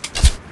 crossbow.ogg